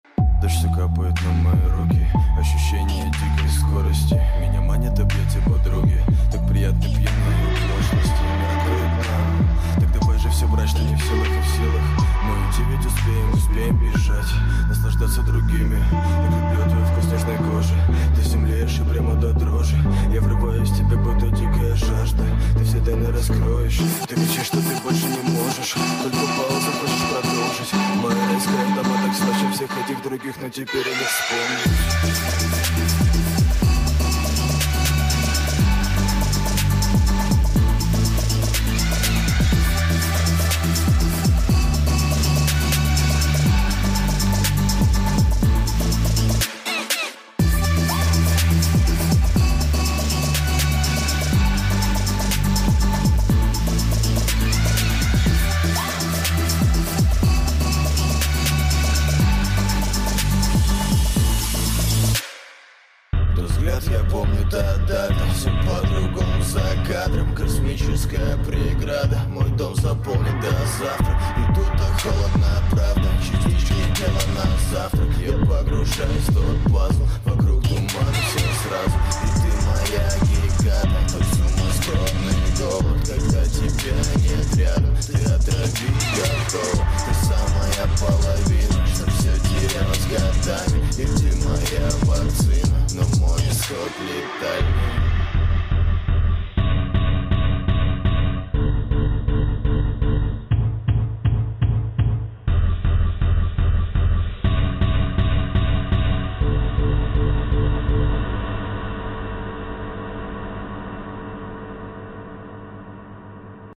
• Качество: 320, Stereo
атмосферные
спокойные
Trap
Chill Trap